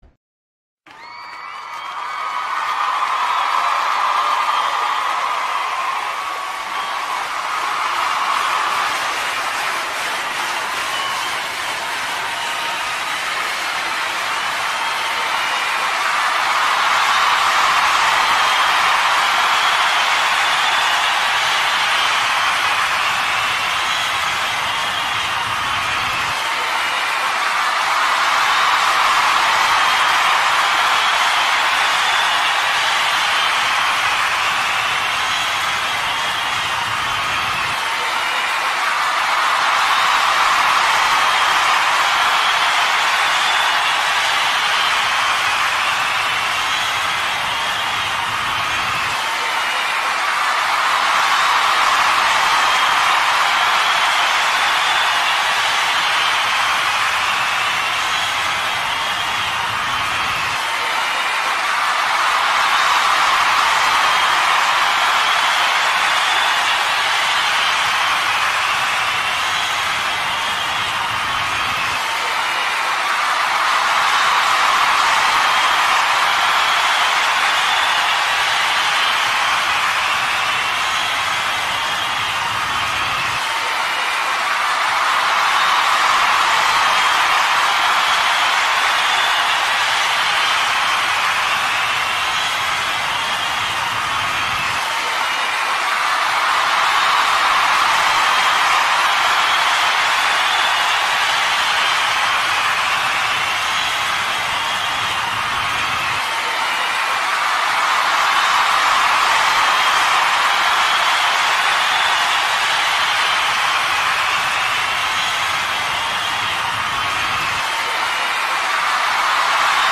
دانلود صدای شادی حضار و تماشاگران جشن کنسرت از ساعد نیوز با لینک مستقیم و کیفیت بالا
جلوه های صوتی
برچسب: دانلود آهنگ های افکت صوتی انسان و موجودات زنده دانلود آلبوم صدای دست جیغ و هورا از افکت صوتی انسان و موجودات زنده